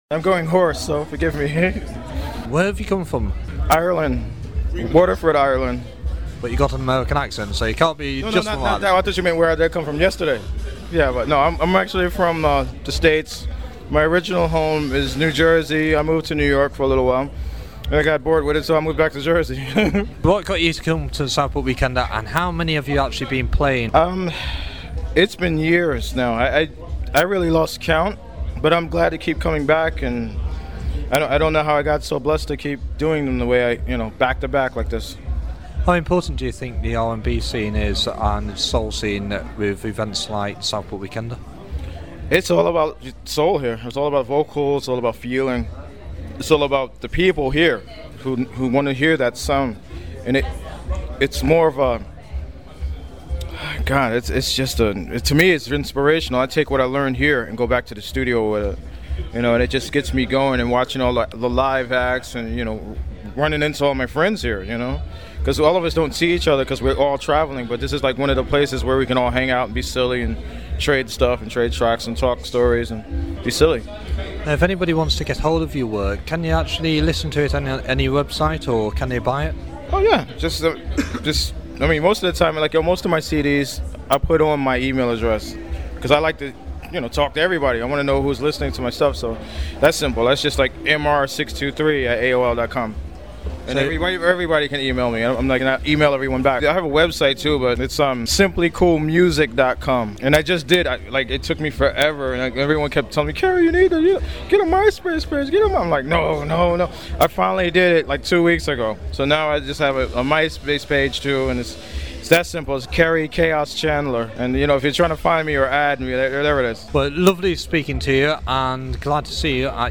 Interview with Real Player Media Player
Iinterview-kerri-chandler.mp3